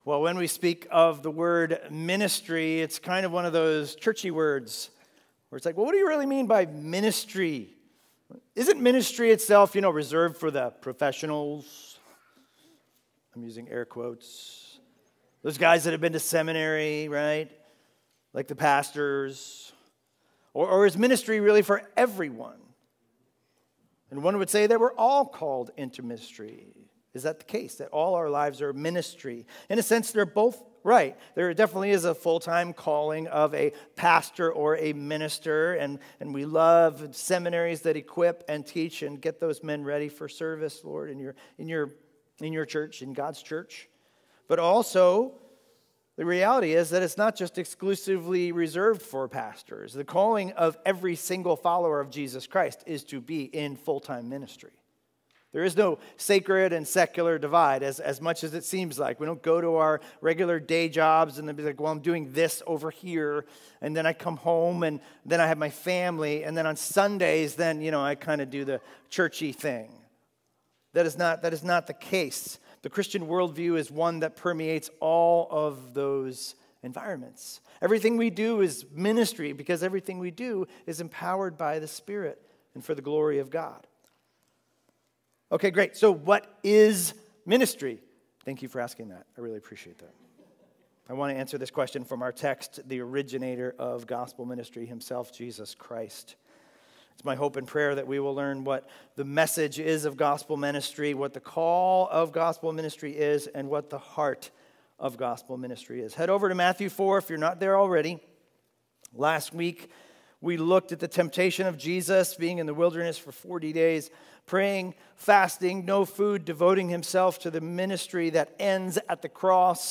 Expositional teaching series through the book of Matthew - starting Sunday, Dec 6, 2020